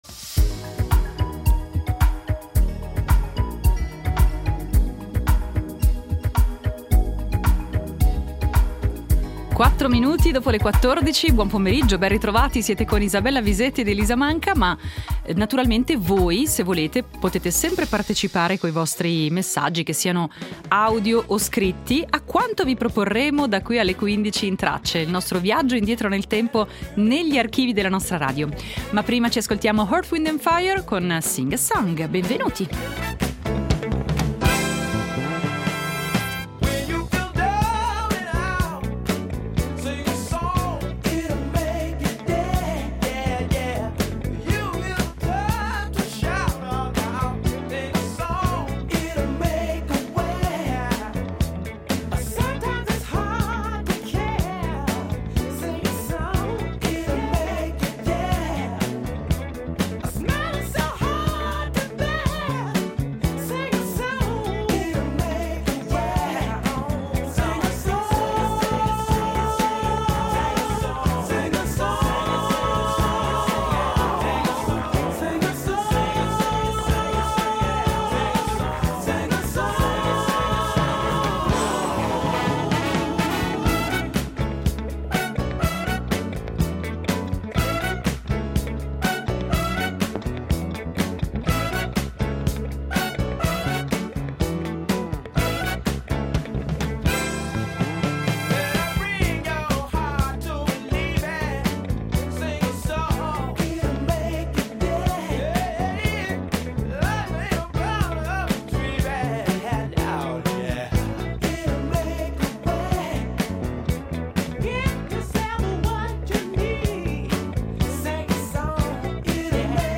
In Tracce , estratti d’archivio sullo scrittore Robert Louis Stevenson; sulla Svezia, che oggi nel 1994 diventa il 15° membro dell’Unione Europea; e sul regista Vittorio De Sica, che ci ha lasciato oggi nel 1974.